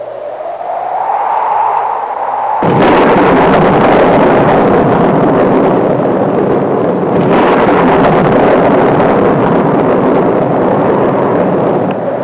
Wind And Thunder Sound Effect
Download a high-quality wind and thunder sound effect.
wind-and-thunder.mp3